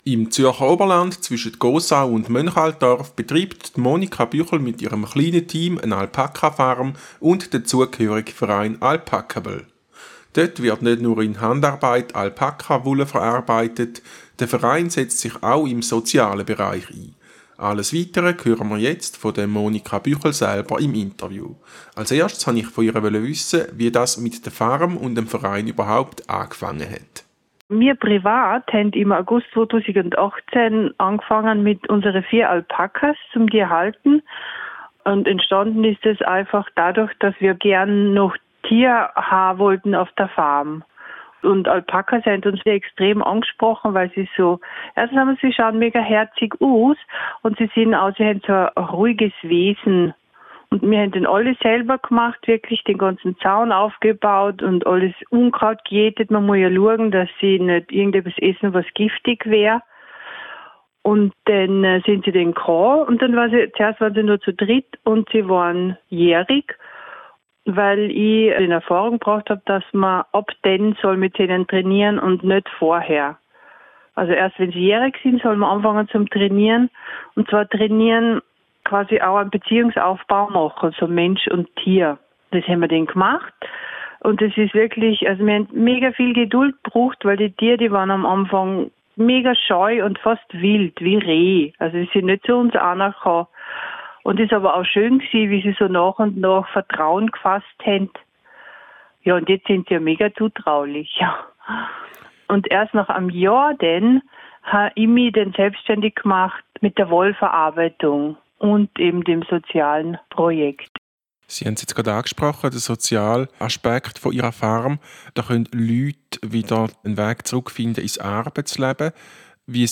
Mehr erfahren Video laden YouTube immer entsperren Artikel in der Bauernzeitung Artikel im Tagblatt Alpaka Farm Artikel im Zürcher Oberländer Interview Radio Zürisee Radio-Interview Alpaka Farm